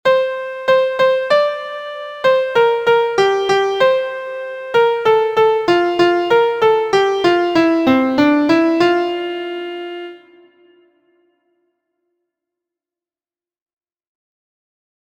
• Origin: USA – American Folk Song
• Key: F Major
• Time: 2/4
• Form: ABCD
• Musical Elements: notes: half, dotted quarter, quarter, eighth; verse/refrain, vocal slur